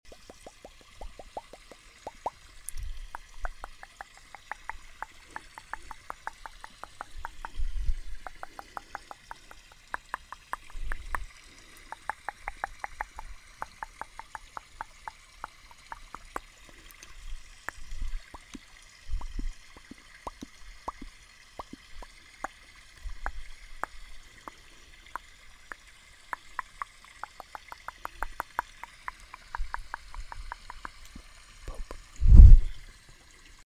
Mouth Sounds & Face Touching sound effects free download
Mouth Sounds & Face Touching ASMR💋👄🤍